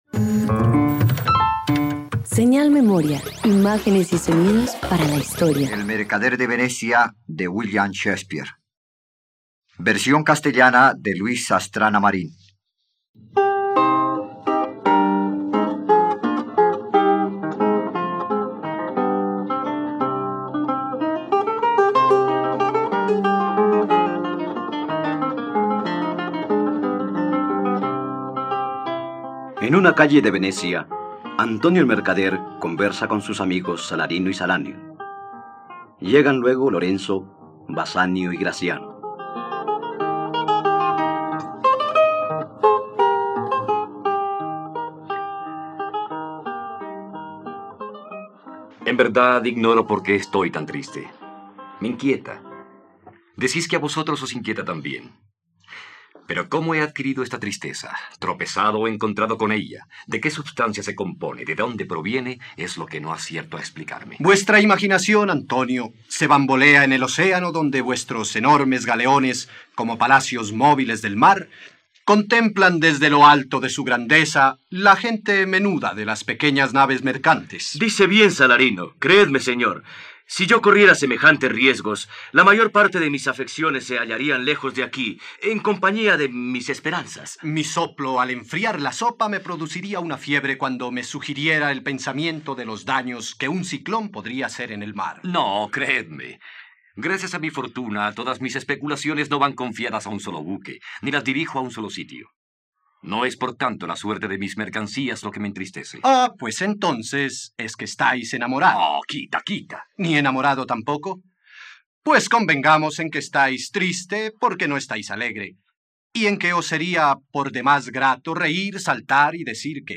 ..Radioteatro. Escucha la adaptación radiofónica de “El mercader de Venecia” adaptada por Bernardo moreno por la plataforma streaming RTVCPlay.